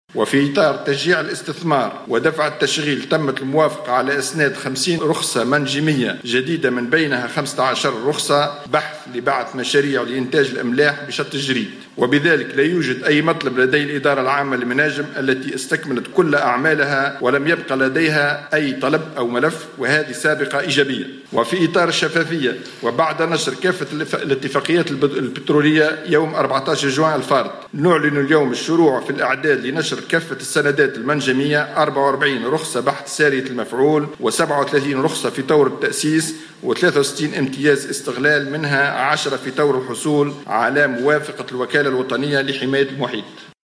أعلن وزير الطاقة والمناجم منجي مرزوق خلال ندوة صحفية بقصر الحكومة بالقصبة اليوم الخميس 14 جويلية 2016 عن إسناد 50 رخصة منجمية جديدة من بينها 15 رخصة لبعث مشاريع لإنتاج الأملاح بشط الجريد.